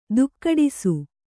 ♪ dukkaḍisu